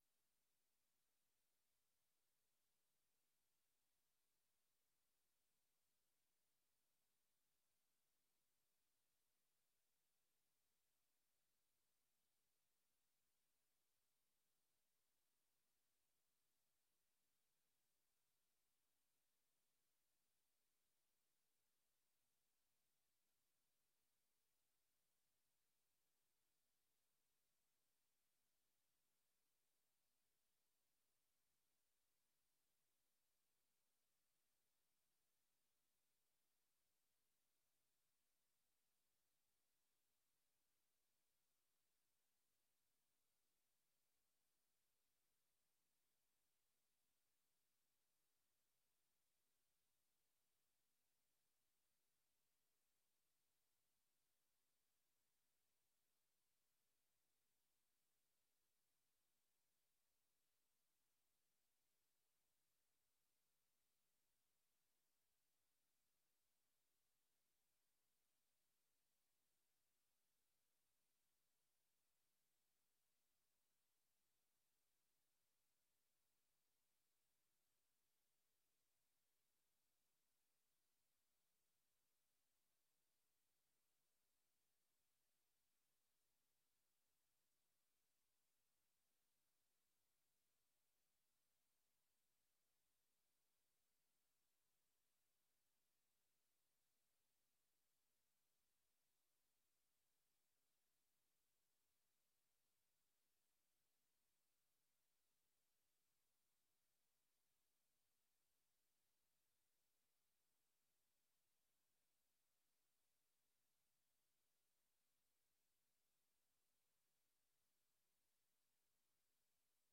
Agendacommissie 15 september 2025 18:00:00, Gemeente West Betuwe
Download de volledige audio van deze vergadering
Locatie: Voorrondezaal Lingewaal